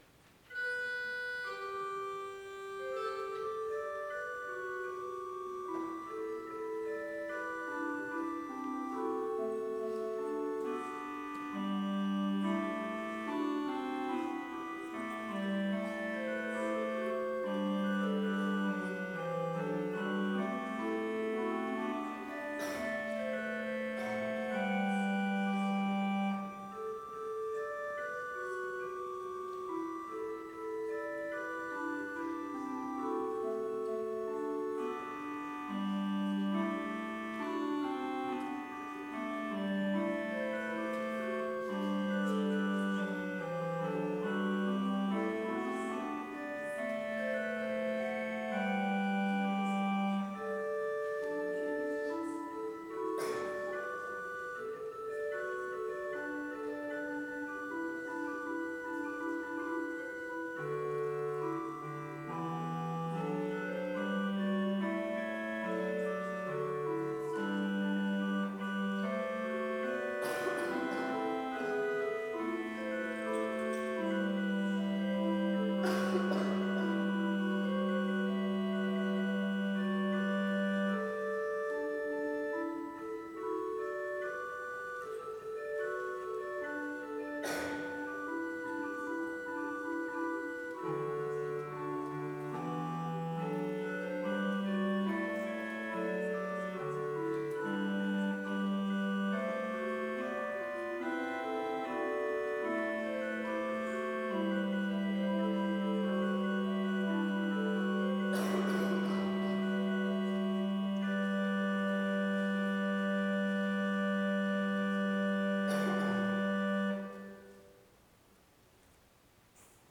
Complete service audio for Vespers - Wednesday, February 26, 2025